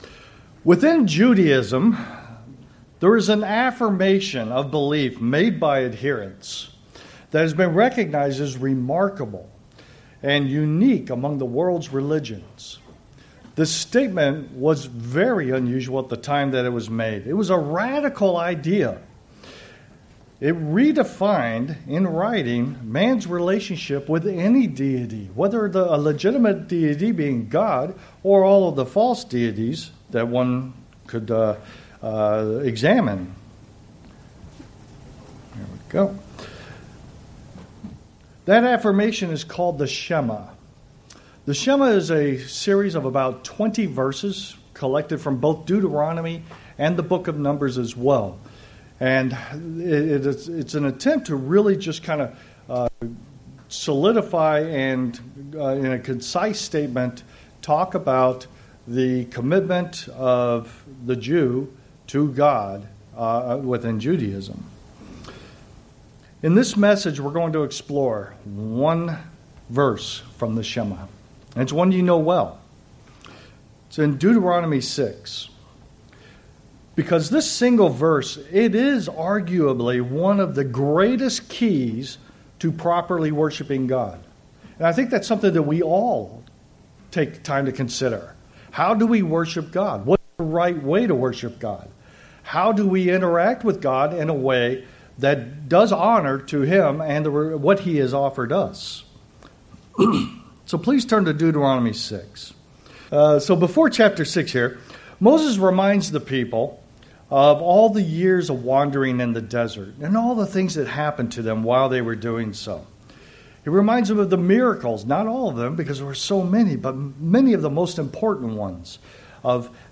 Sermons
Given in Chicago, IL